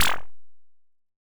bloop.mp3